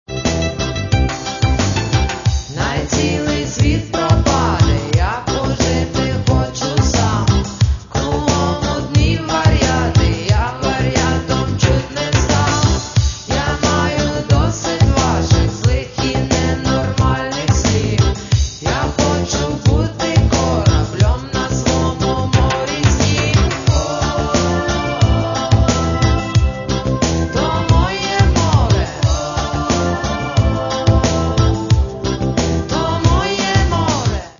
Каталог -> Поп (Легкая) -> Юмор
Легкая и энергичная музыка, шутка в каждой строке.